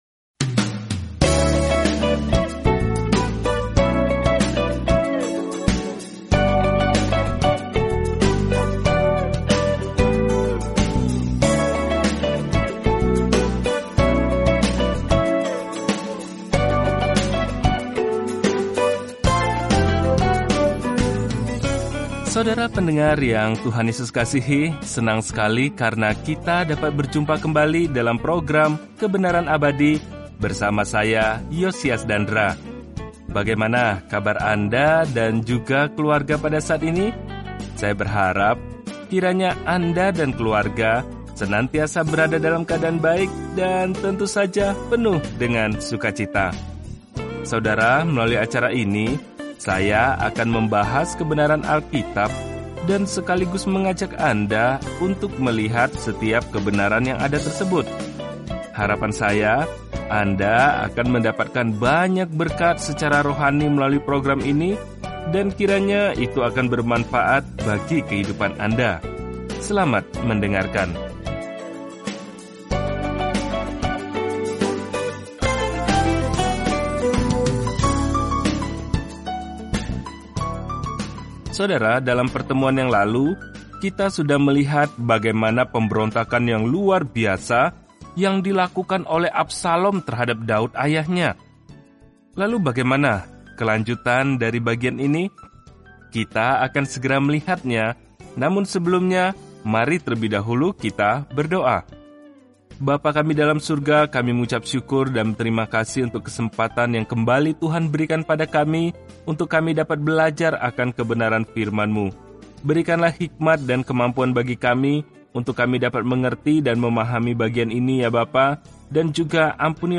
Firman Tuhan, Alkitab 2 Samuel 17 2 Samuel 18 Hari 9 Mulai Rencana ini Hari 11 Tentang Rencana ini Kisah hubungan Israel dengan Tuhan berlanjut dengan diperkenalkannya para nabi pada daftar bagaimana Tuhan terhubung dengan umat-Nya. Telusuri 2 Samuel setiap hari sambil mendengarkan pelajaran audio dan membaca ayat-ayat tertentu dari firman Tuhan.